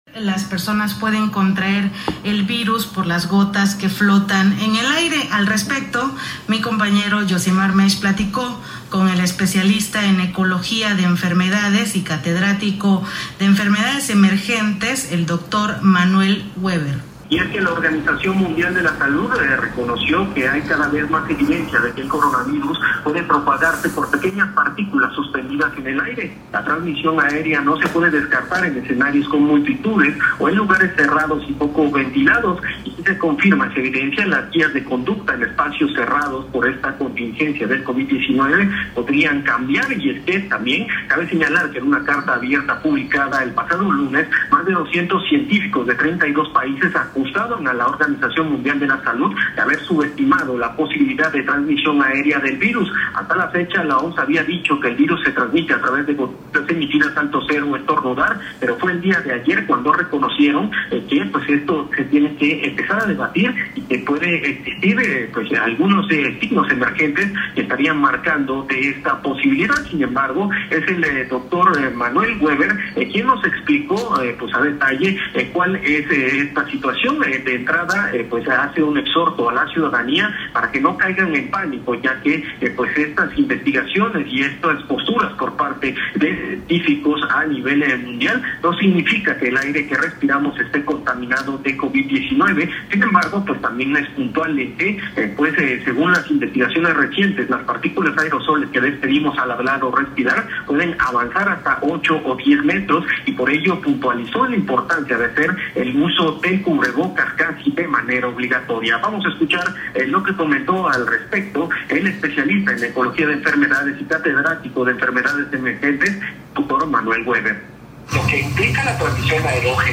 Entrevista
en noticiero de Núcleo Comunicación del Sureste, en ella se aborda la importancia de usar cubre bocas ante la pandemia del COVID-19